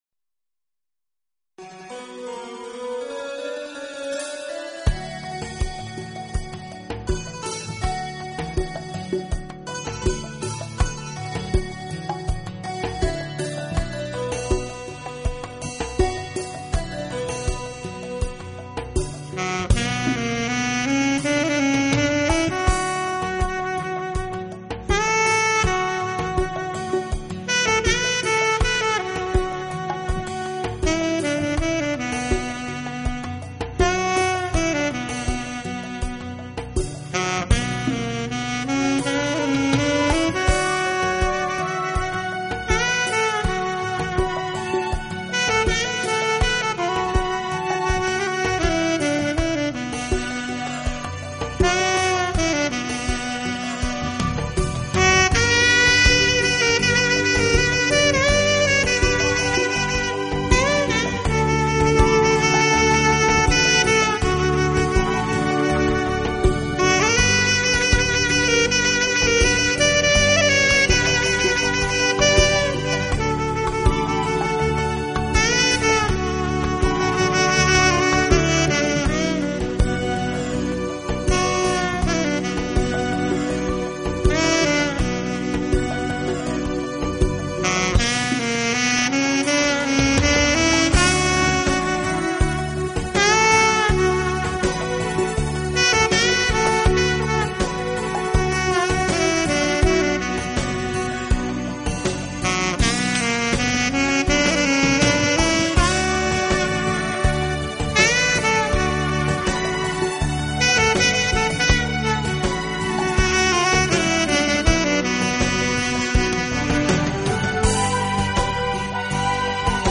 浪漫萨克斯
一杯咖啡伴随一首萨克斯音乐，让我们一起度过这最浪漫的时刻……